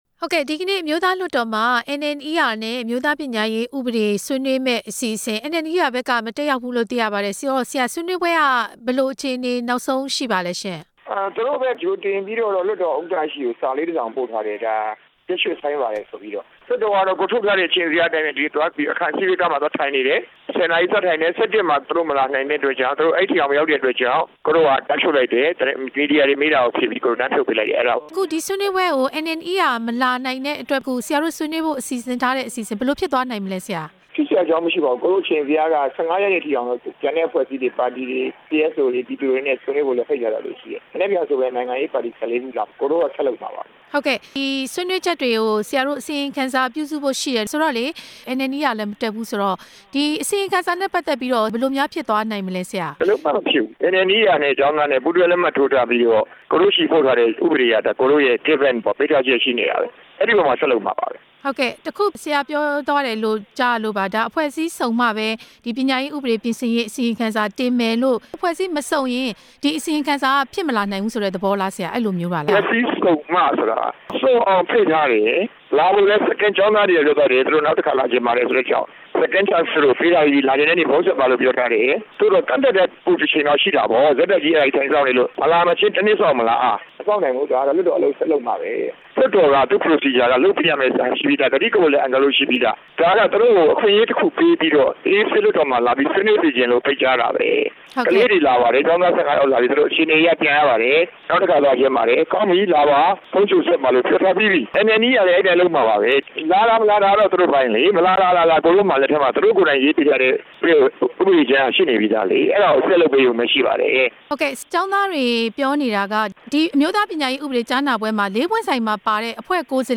ဥပဒေကြမ်းကော်မတီ ဥက္ကဌ ၊ NNER နဲ့ ကျောင်းသားခေါင်းဆောင်တွေကို ဆက်သွယ်မေးမြန်းပြီး